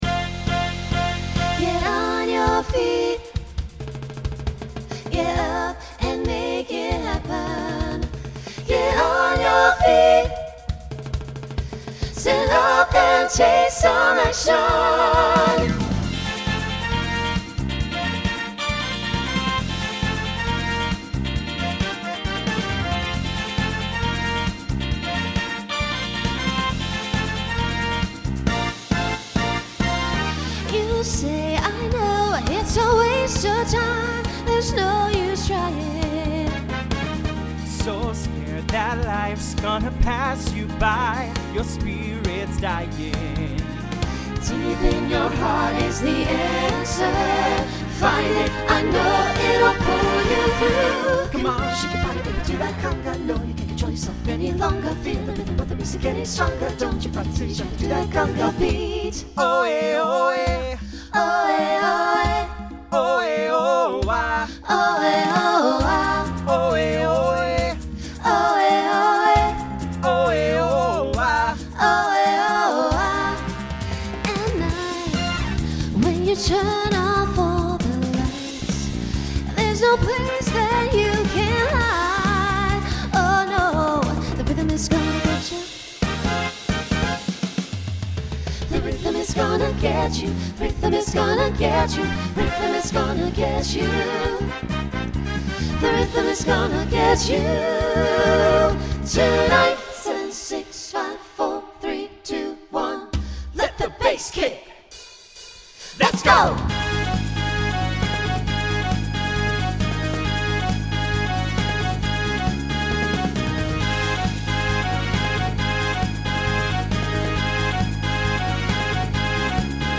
Latin , Pop/Dance Instrumental combo
Voicing SATB